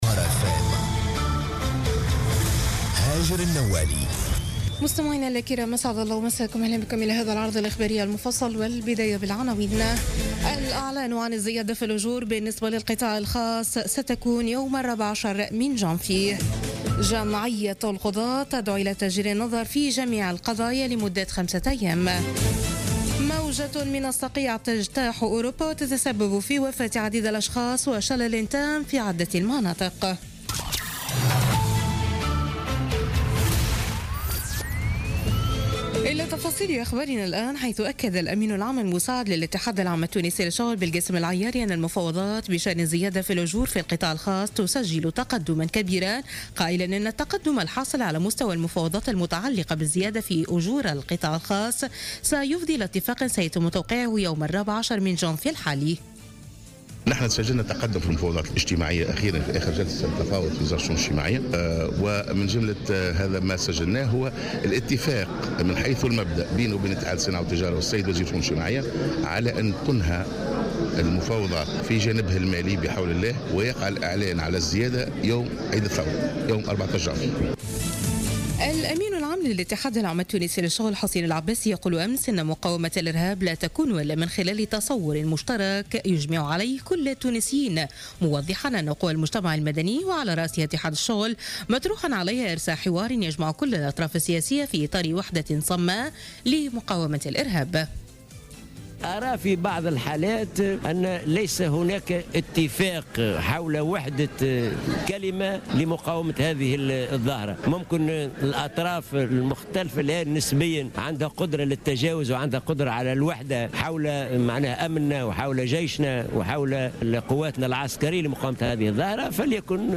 نشرة أخبار منتصف الليل ليوم الأحد 8 جانفي 2017